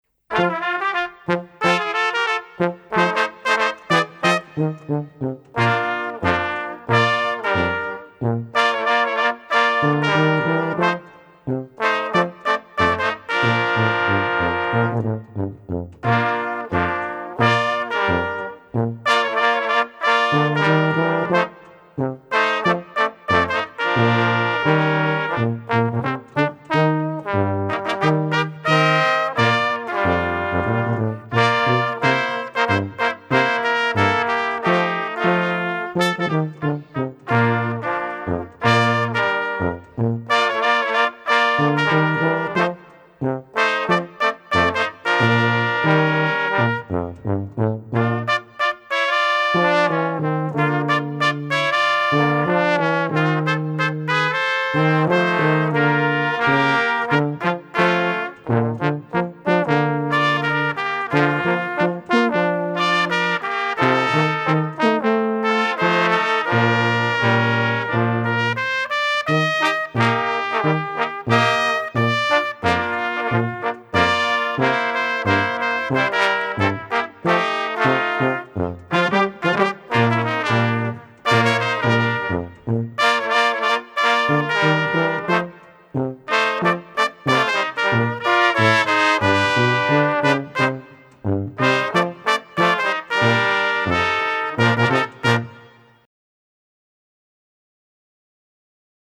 Gattung: 4 Blechbläser
Besetzung: Ensemblemusik für 4 Blechbläser